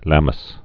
(lăməs)